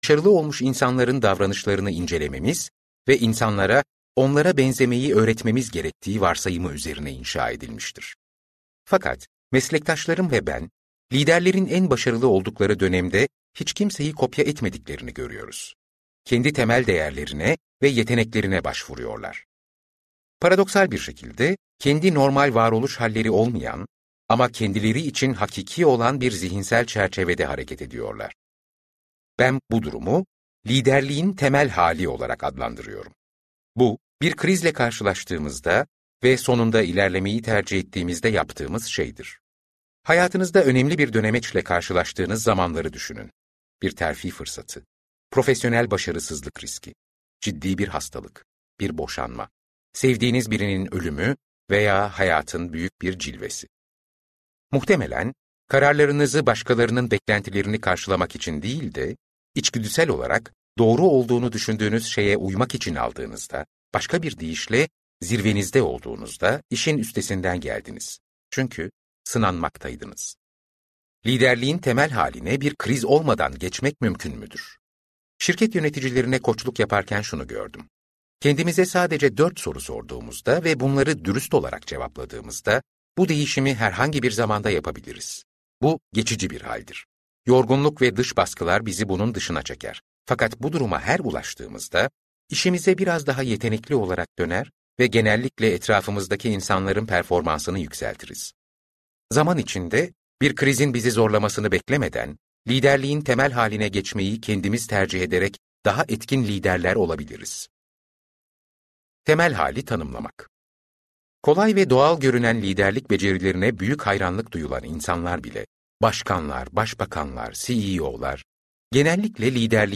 Muhteşemlik Anları - Seslenen Kitap